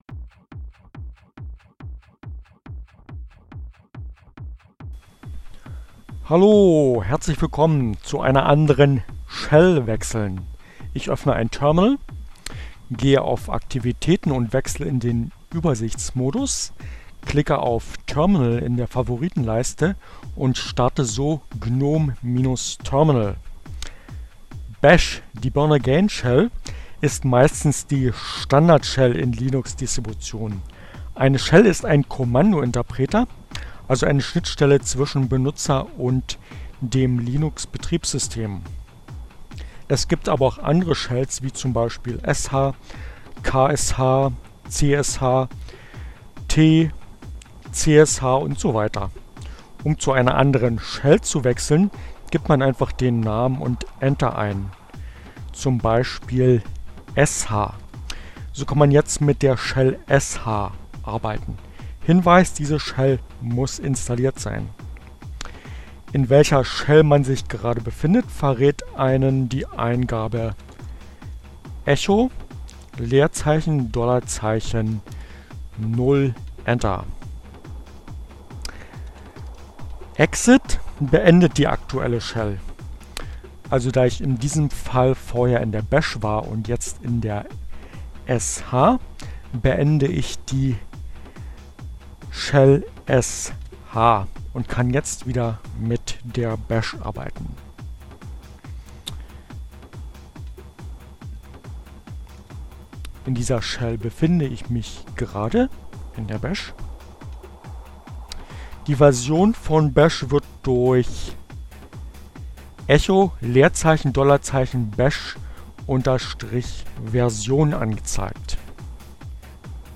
ohne Musik , screencast